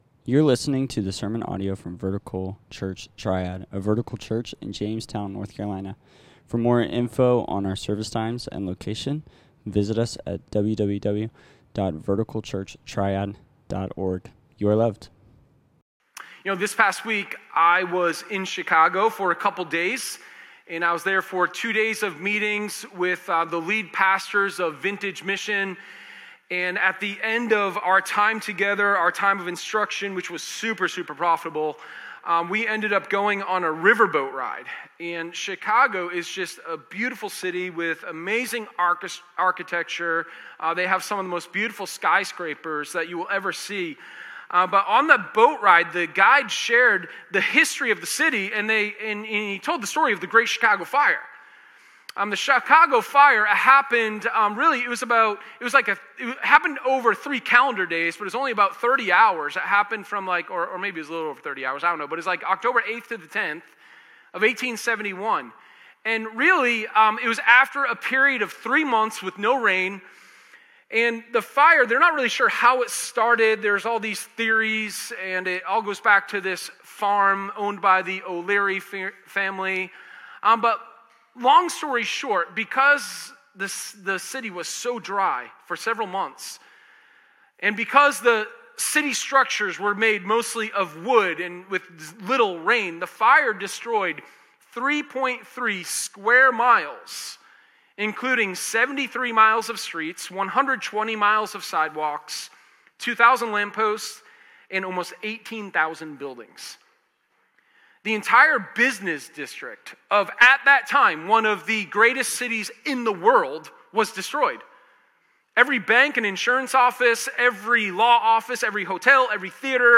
Sermon1030_Life-Without-the-Resurrection.m4a